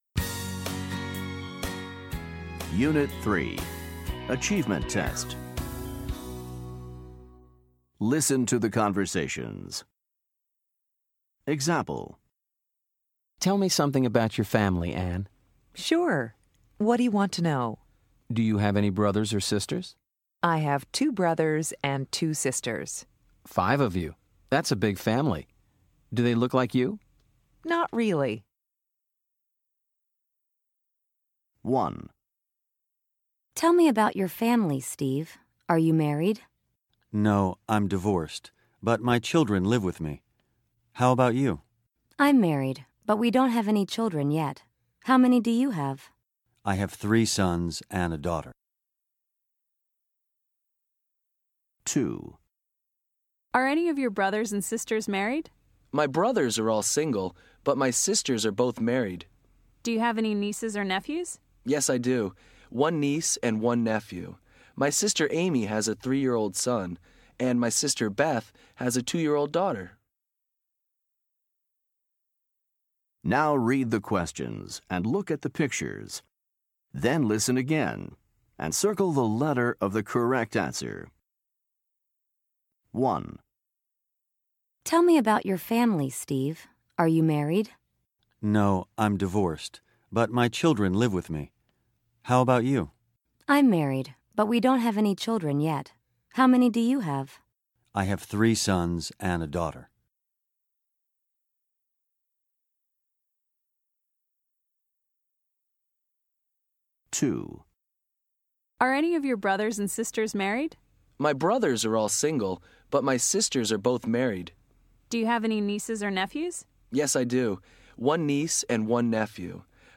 Listen to the conversations and look at the pictures. Choose the correct picture.